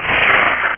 Amiga 8-bit Sampled Voice
PaintbombSticky.mp3